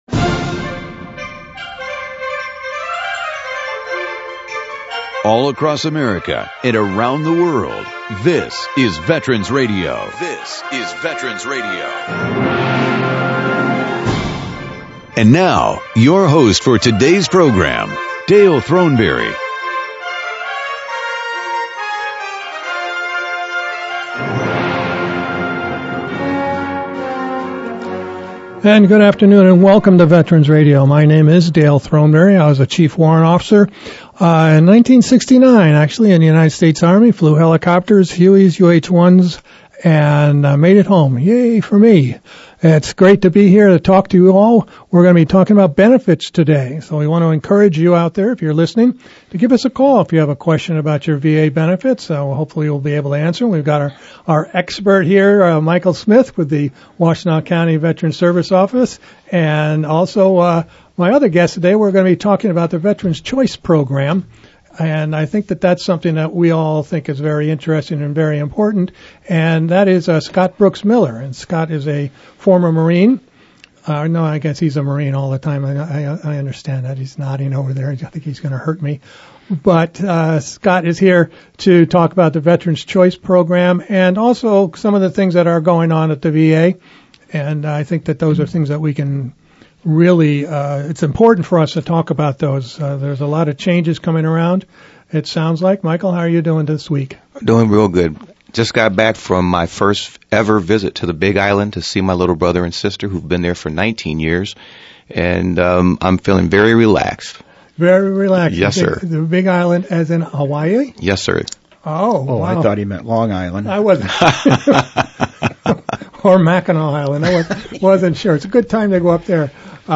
VA benefits experts will bring you up to date on what’s going on at the VA and answer your questions regarding any and all benefits you may be entitled to.